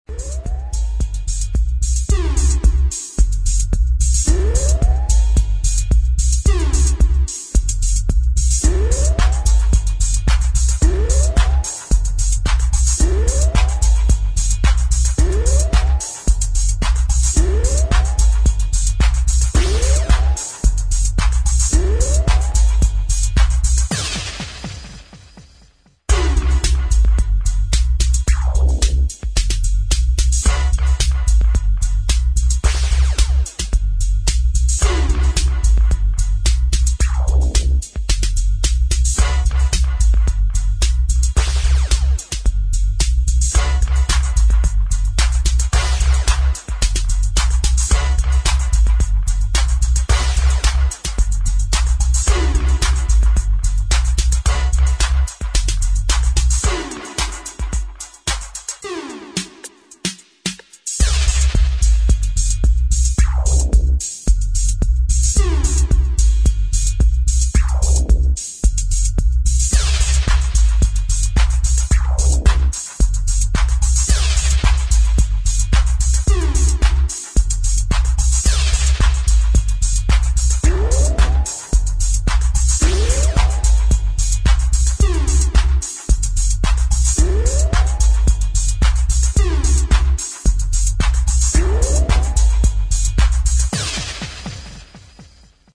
DUB / DISCO / ELECTRONIC